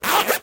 Звуки ширинки
Шепот расстегивающейся ширинки